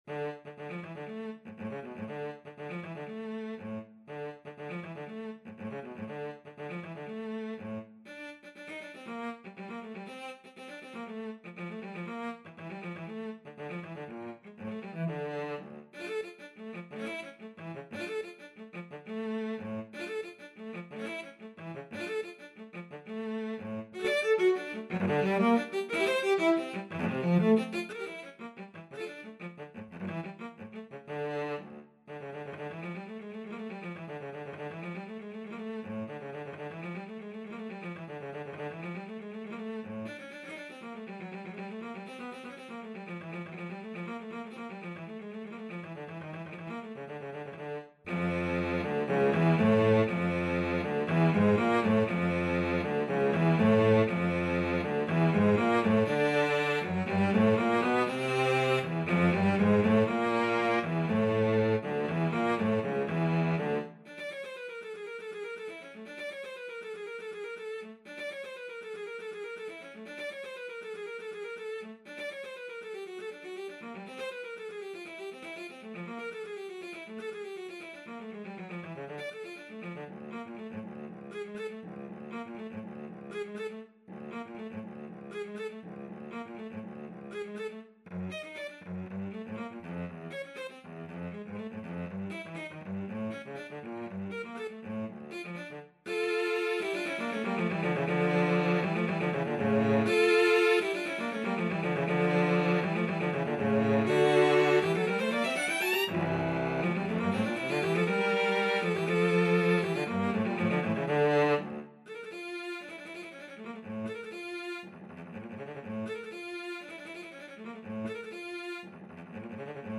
Cello version
2/4 (View more 2/4 Music)
C3-Bb6
Cello  (View more Advanced Cello Music)
Classical (View more Classical Cello Music)